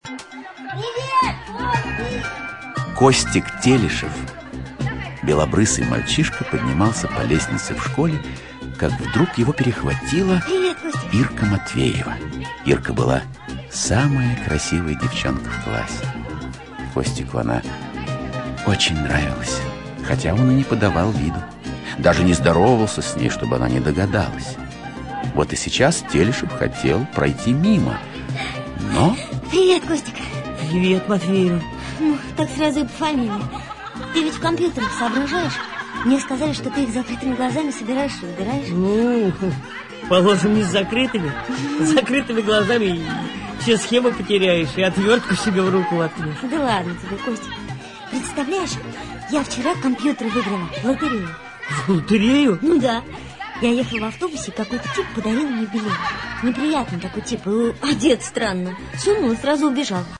Аудиокнига Компьютер звездной империи (спектакль) | Библиотека аудиокниг
Aудиокнига Компьютер звездной империи (спектакль) Автор Дмитрий Емец Читает аудиокнигу Евгений Весник.